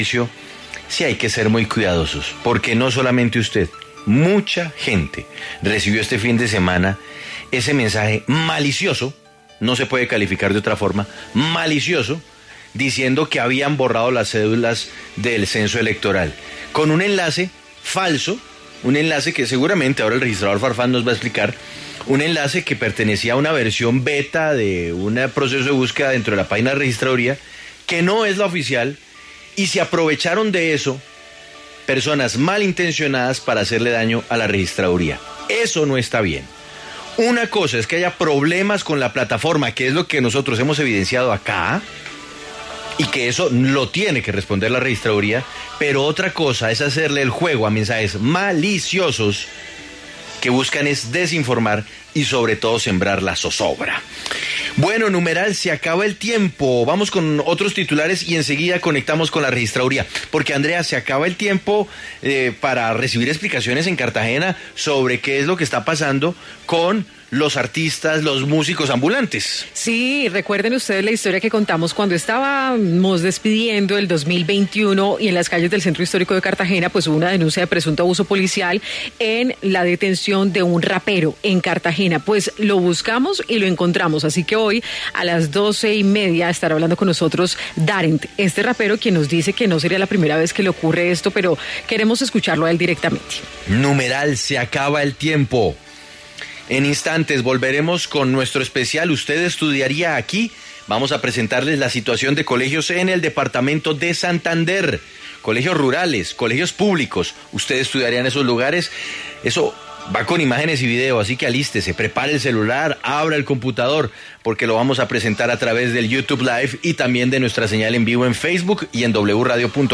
Nicolás Farfán, registrador delegado en lo electoral de la Registraduría, confirmó en Sigue La W que hay una cadena falsa con un link donde se asegura que los ciudadanos deben inscribirse para estar en el nuevo censo.
Ante la alerta, Nicolás Farfán, registrador delegado en lo electoral de la Registraduría, respondió en W Radio y confirmó que se trata de un mensaje malicioso para que los ciudadanos ingresen al link. También aseguró que no es cierto que exista un nuevo censo electoral.